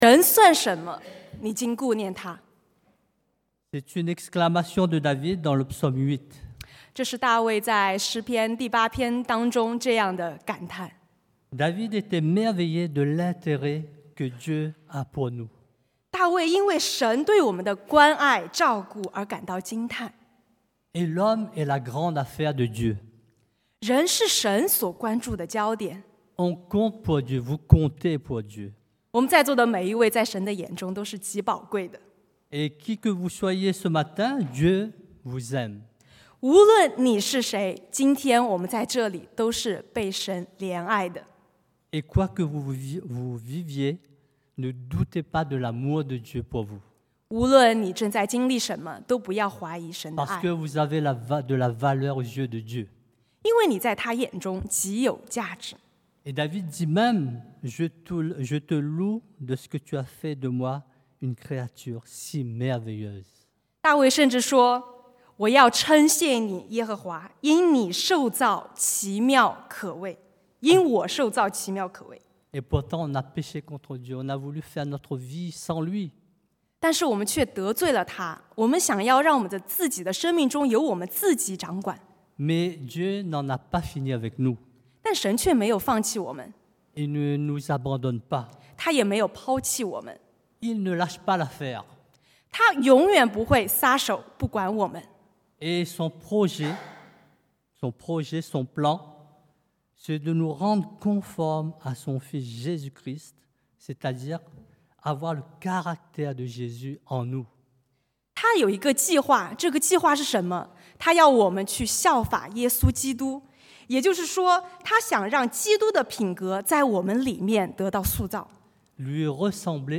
Type De Service: Predication du dimanche